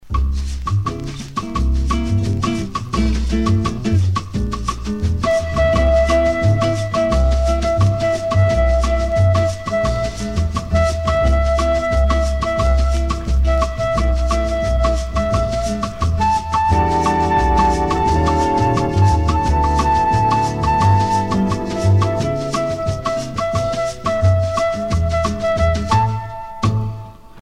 danse : bossa nova
Pièce musicale éditée